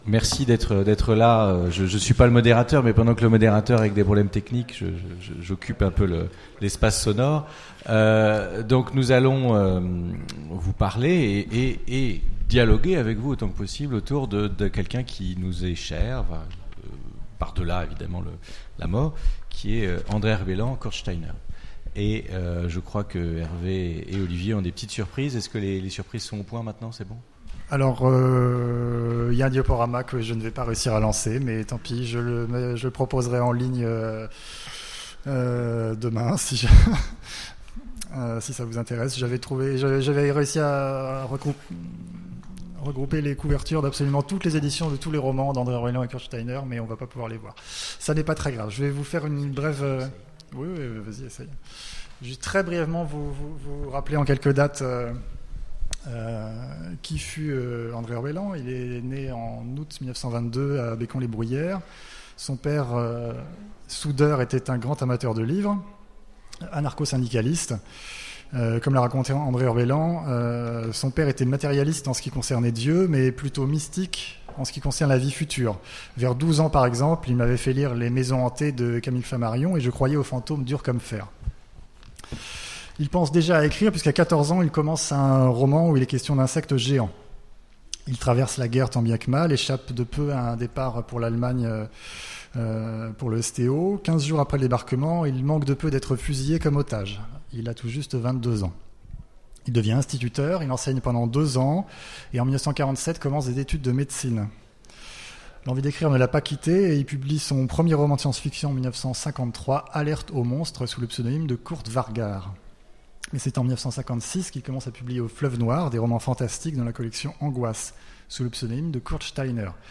Utopiales 2017 : Conférence Hommage à André Ruellan